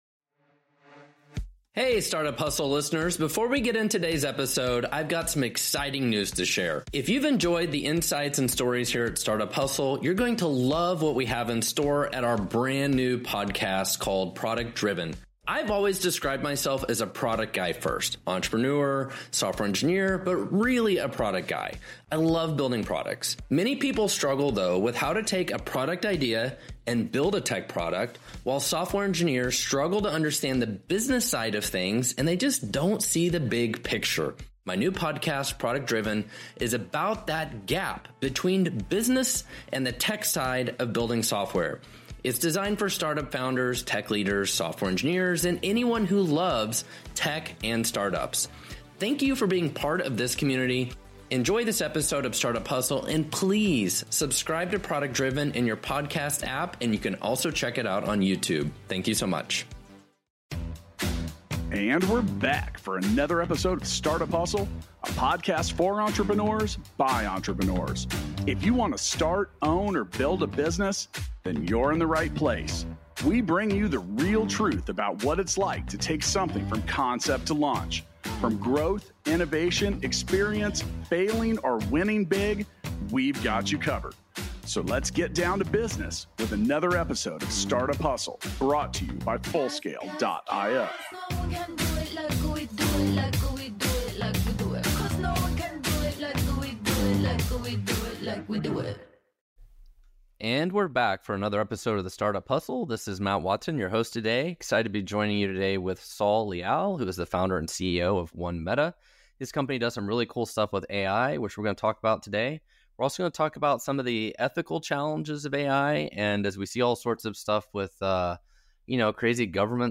for a discussion about AI's ethical challenges.